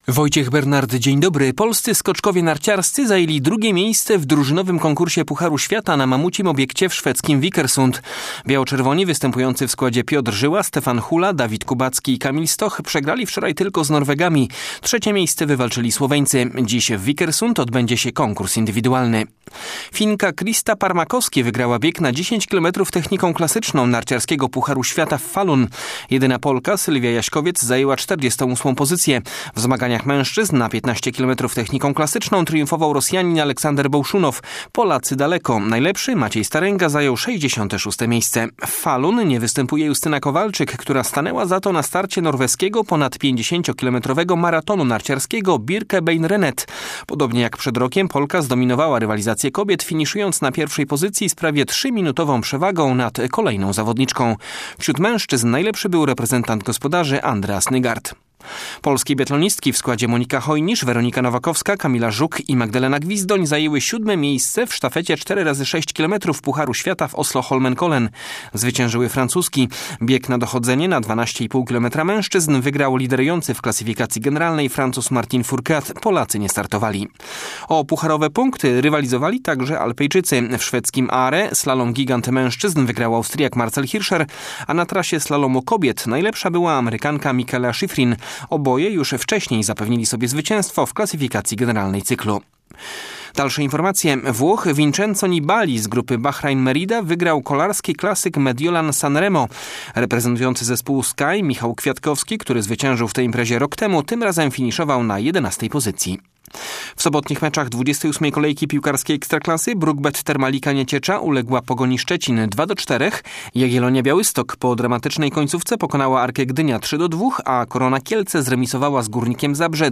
18.03 serwis sportowy godz. 9:05